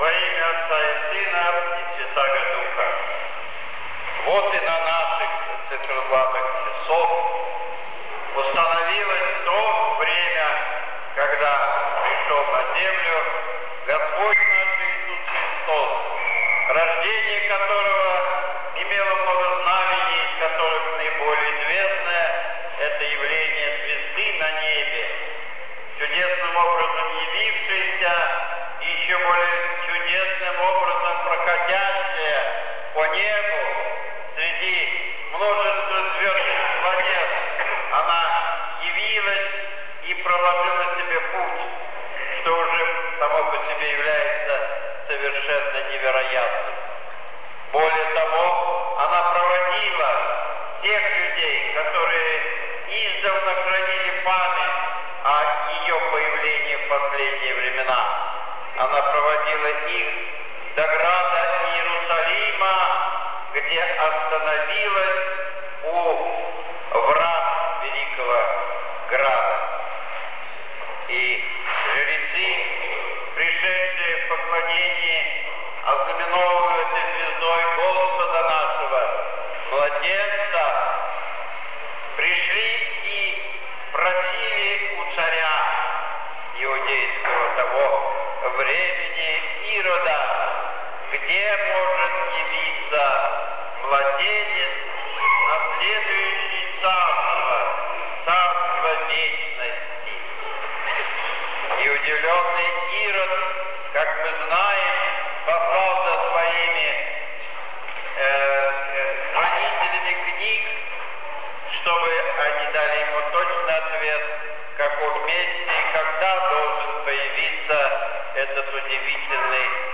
7 января 2014 г. Борисово. Приход церкви во имя Покрова Божией Матери